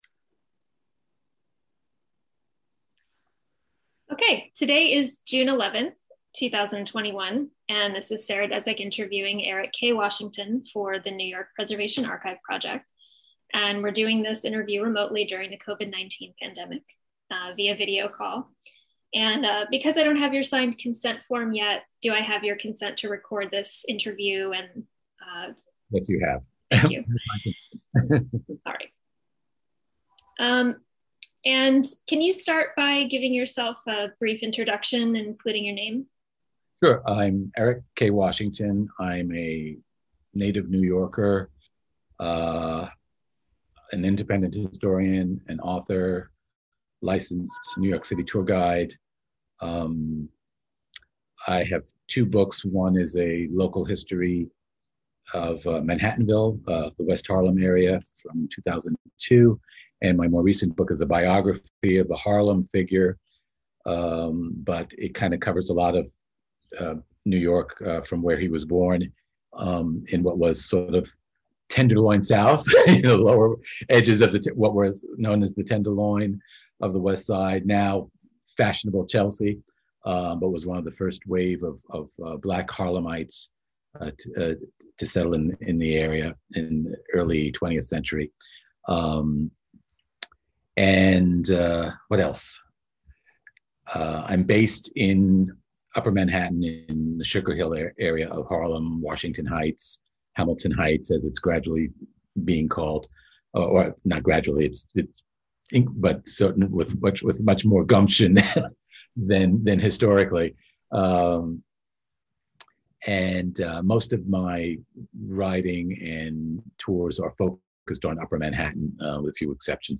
Oral History Intensive